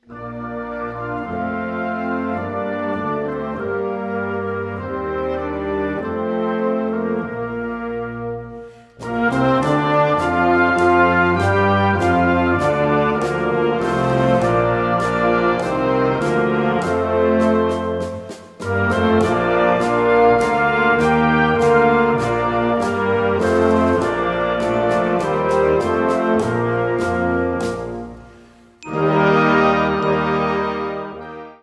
Kategorie Blasorchester/HaFaBra
Unterkategorie Suite
Piano 1
Mallet 1
Percussion 1 1